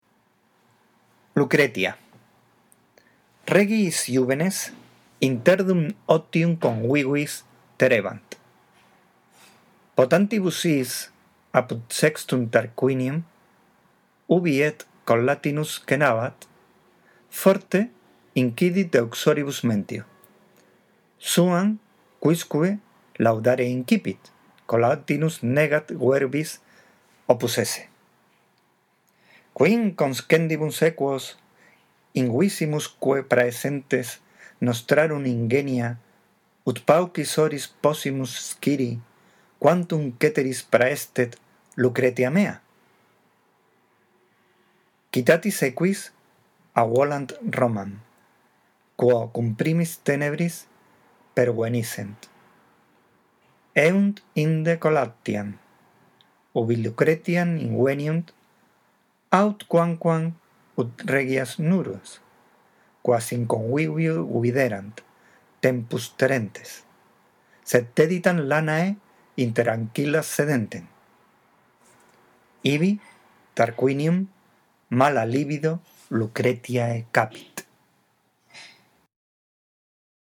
La audición de este archivo te ayudará en la práctica de la lectura